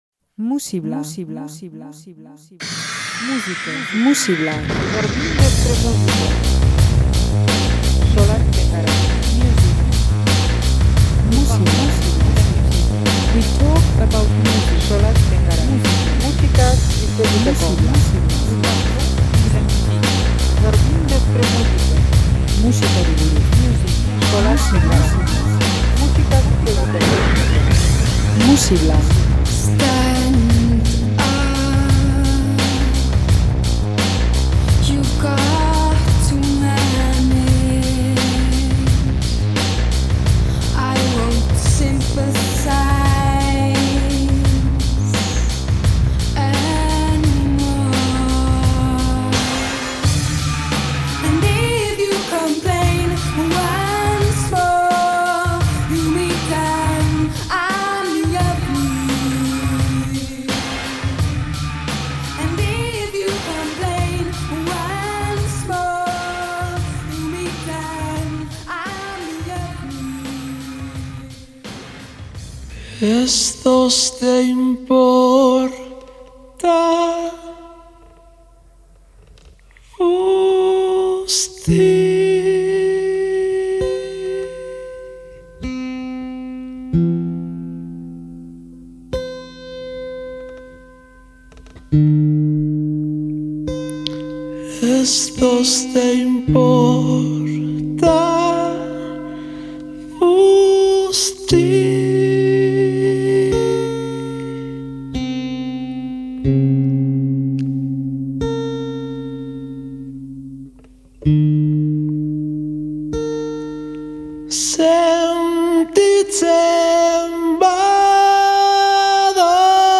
Sentsibilitatea eta abesteko era ederra
bere tempo geldo eta zoragarriekin.